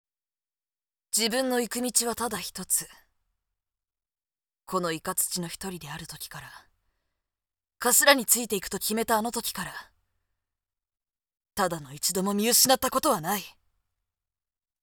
【サンプルセリフ】
（これからの行く先を見据え）